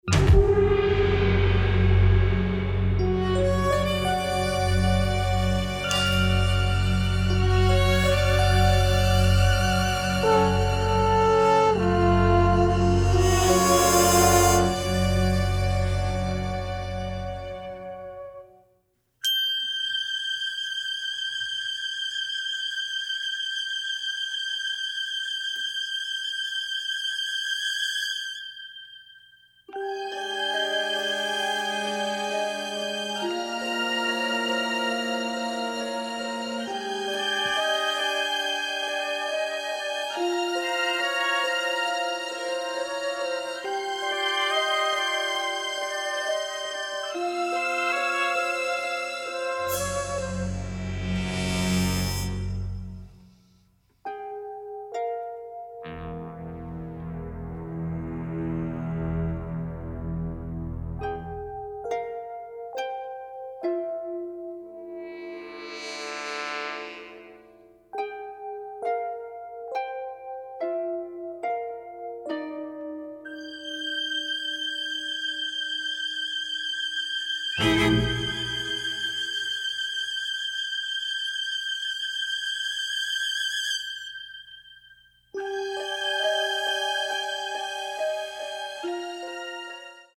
stereo presentation
original motion picture score